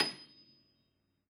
53h-pno27-D6.wav